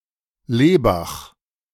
Lebach (German pronunciation: [ˈleːbax]
De-Lebach.ogg.mp3